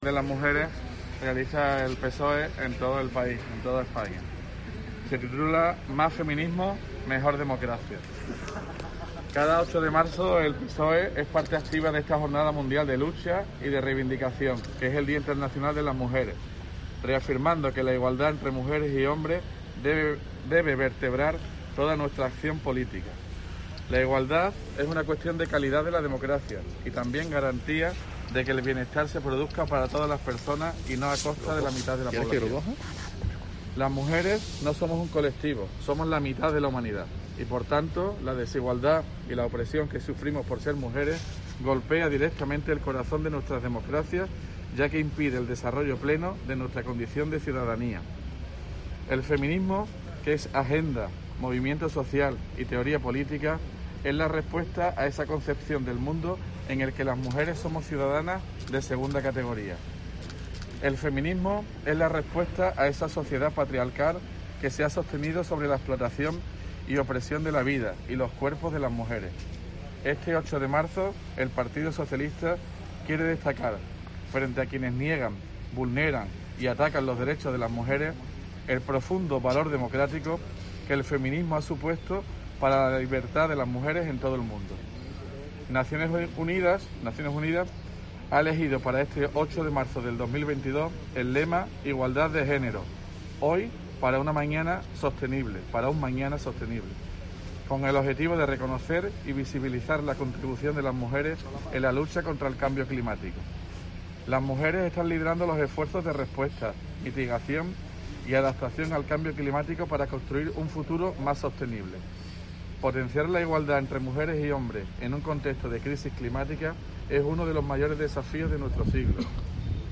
El PSOE de la provincia de Cádiz, ha dado lectura a su manifiesto por el 8M, Día Internacional de las Mujeres